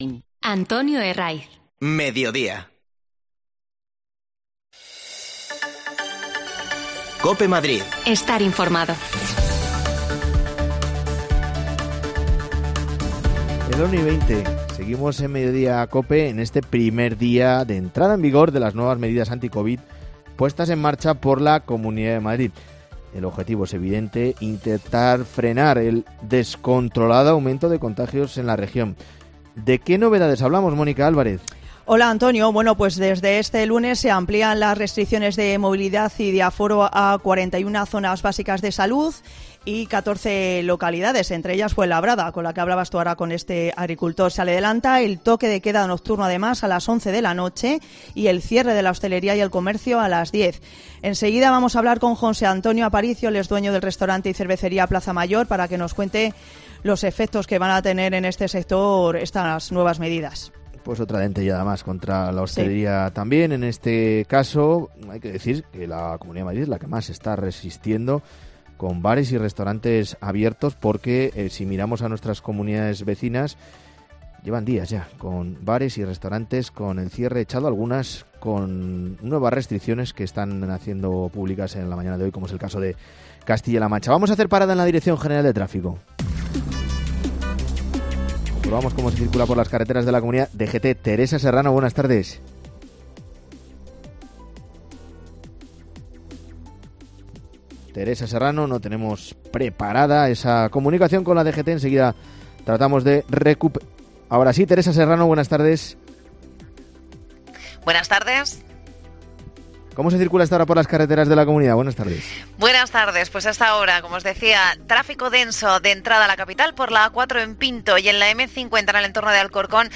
AUDIO: Se adelanta el cierre de la hosteleria a las 22 horas. Hablamos con hosteleros afectados por esta nueva medida y por la borrasca Filomena
Las desconexiones locales de Madrid son espacios de 10 minutos de duración que se emiten en COPE , de lunes a viernes.